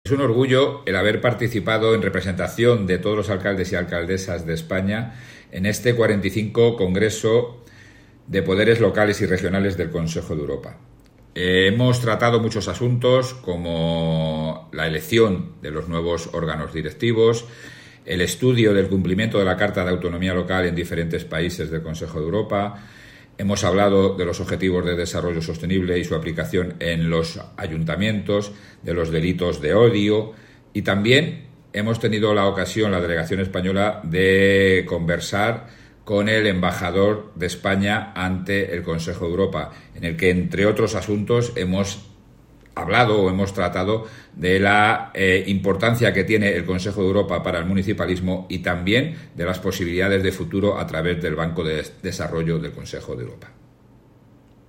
Declaraciones del alcalde José Luis Blanco